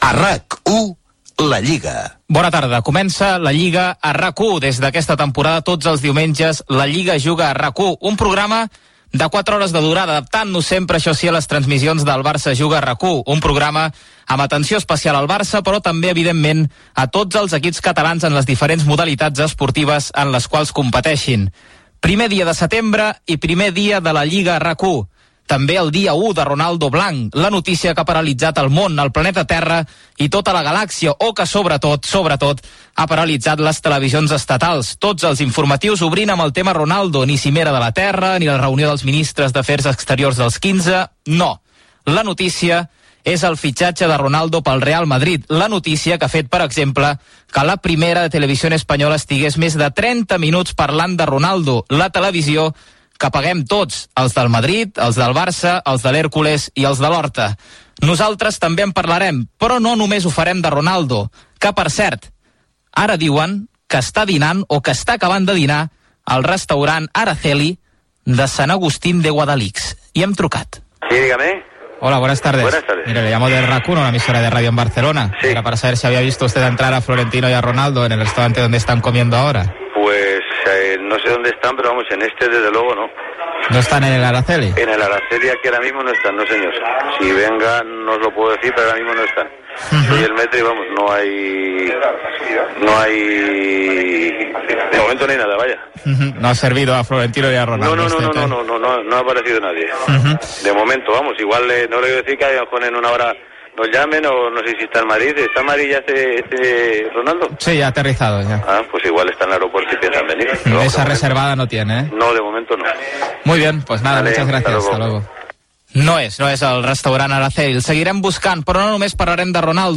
Indicatiu del programa, presentació de la primera edició.
Esportiu